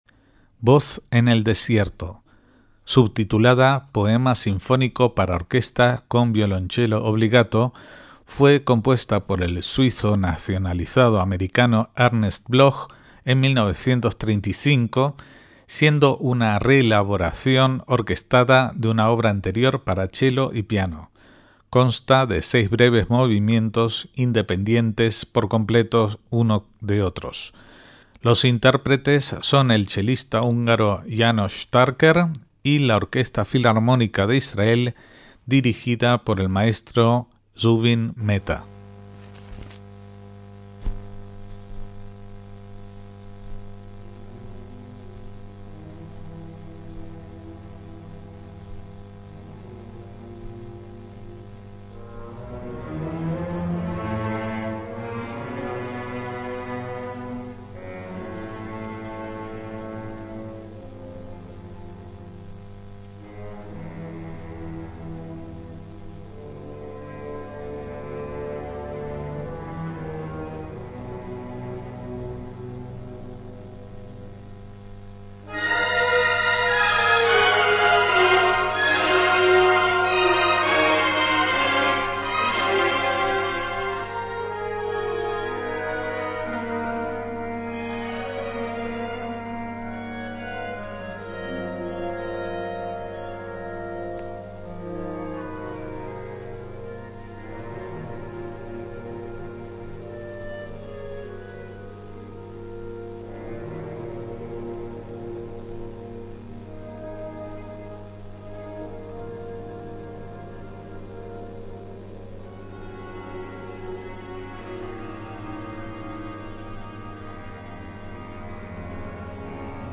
MÚSICA CLÁSICA - Voz en el desierto es la última partitura de inspiración judía que compuso el suizo nacionalizado estadounidense Ernst Bloch, basada en un versículo del profeta Isaías, 40:3. Como en otra obras anteriores, el chelo es la voz que clama en el desierto. Está obra , subtitulada ‘Poema sinfónico para orquesta con violonchelo obbligato’, fue compuesta en 1935.
Consta de seis movimientos breves con un tema que reaparece varias veces: Moderato (poco lento), Poco lento, Moderato, Adagio piacevole, Poco agitato y Allegro.